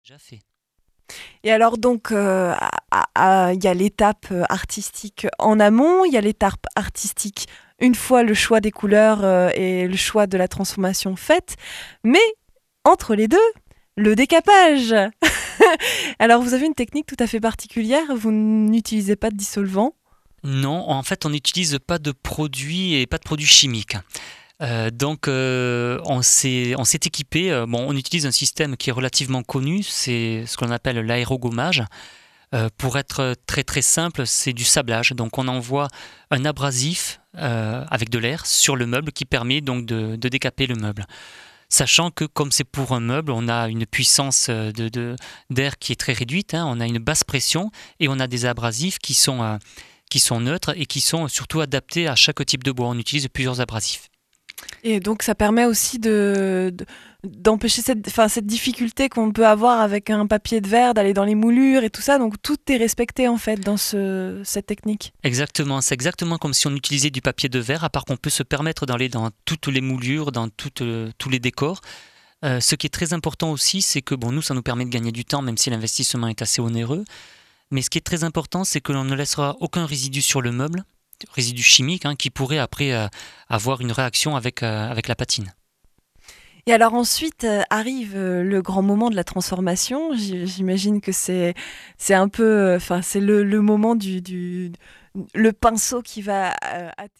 Lors d’une interview radiophonique Rouge Indigo décrit les avantages de l’aérogommage: